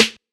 Snr (Polow).wav